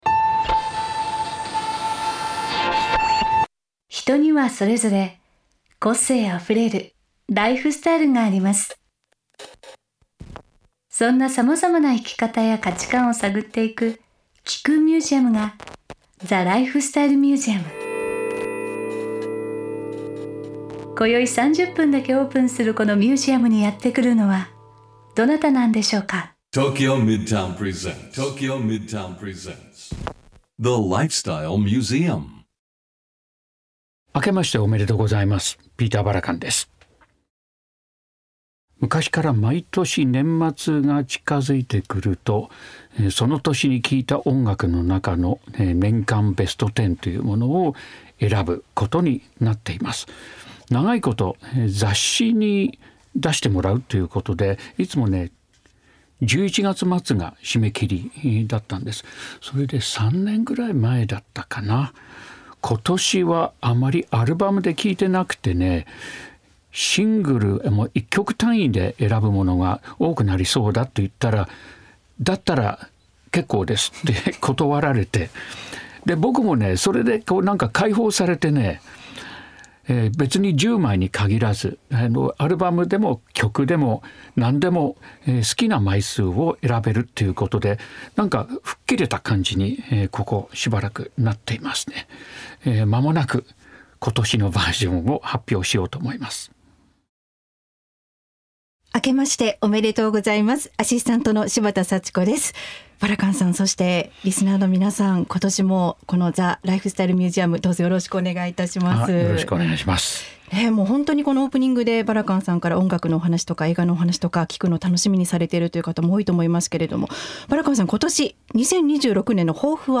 ピーター・バラカン氏がメインパーソナリティーとなり、毎回様々なゲストを迎えて生き方や価値観を探っていくゲストトーク番組。